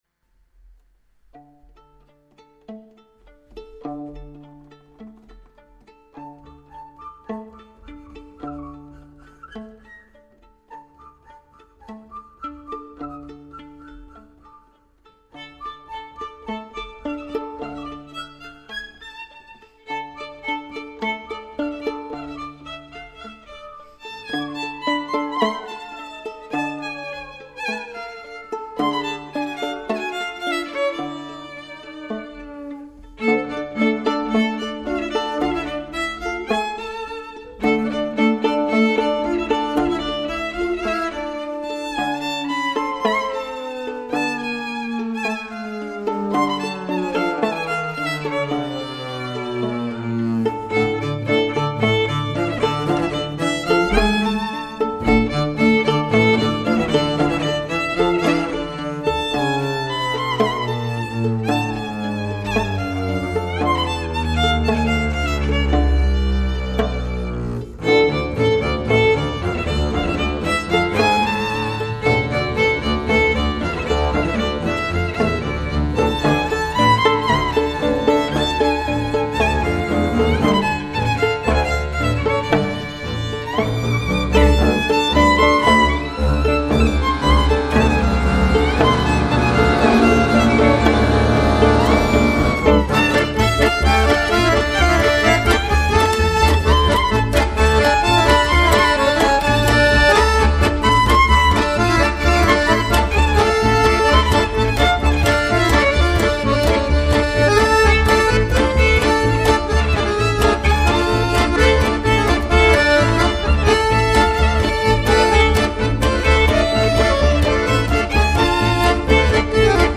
violon, chant, mandoline
alto, chant, xaphoon, guitare
accordéon, chant, darbouka, tambour
contrebasse, chant, flûte traversière
Klezmer